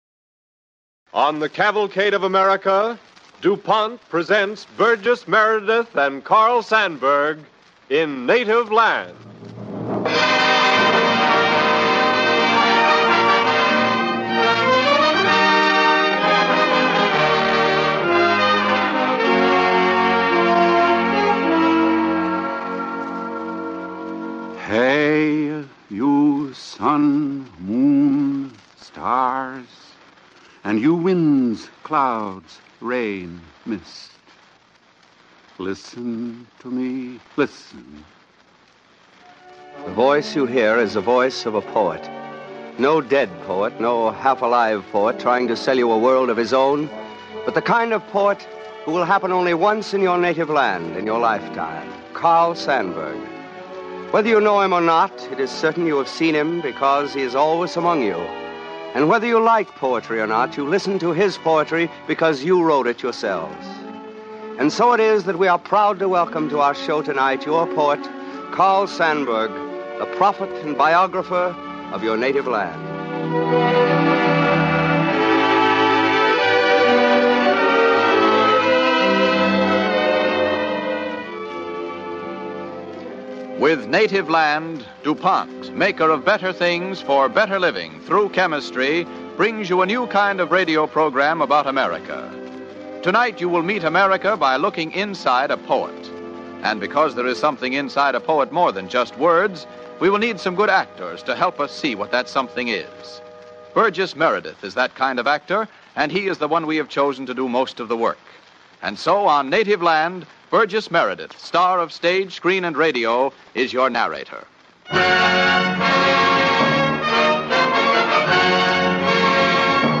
starring Burgess Meredith
Cavalcade of America Radio Program